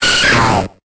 Cri de Kapoera dans Pokémon Épée et Bouclier.